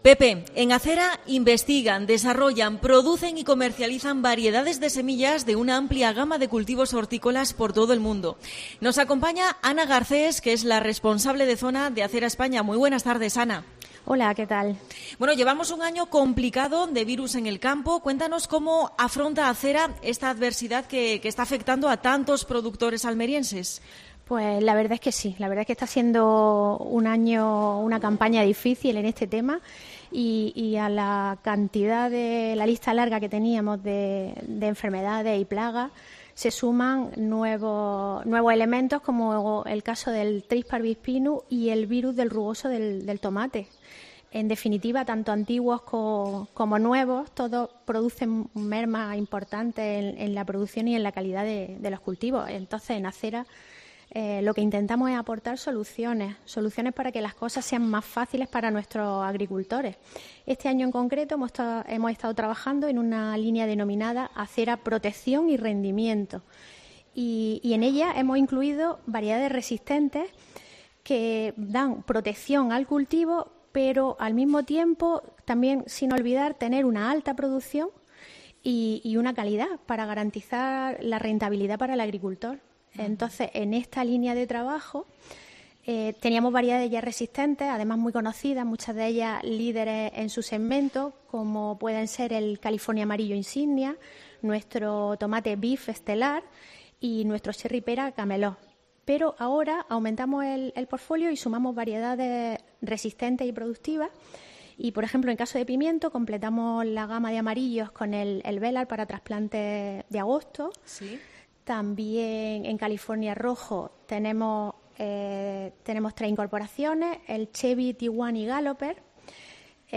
Especial semillas: entrevista